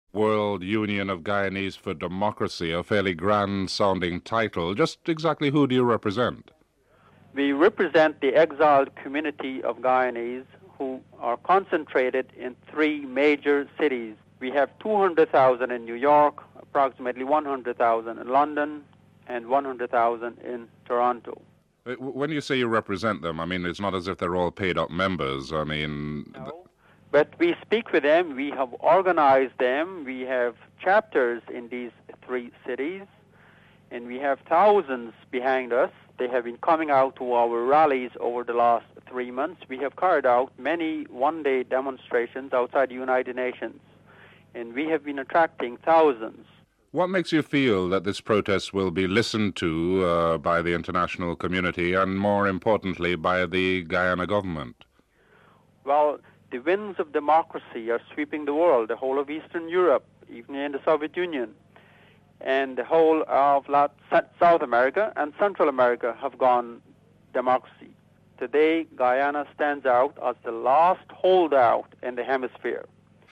Reports begins in the first segment and ends abruptly.